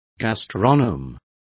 Complete with pronunciation of the translation of gastronome.